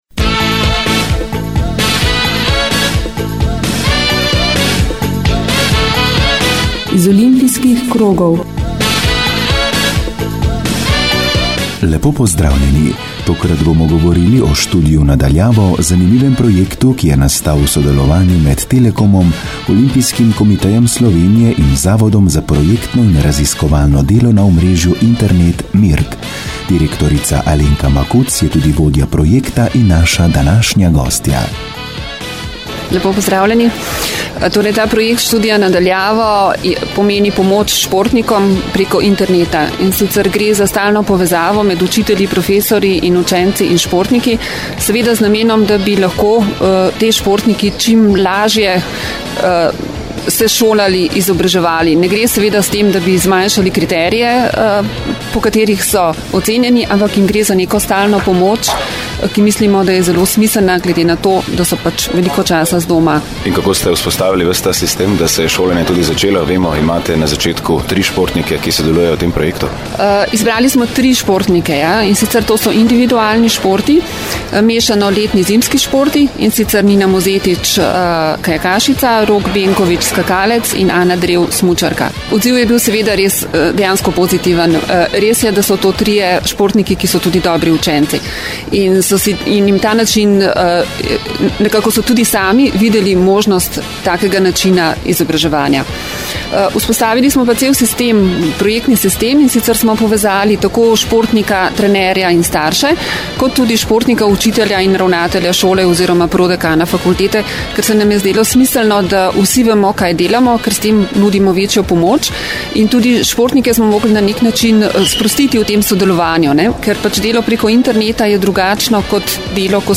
Radijski intervju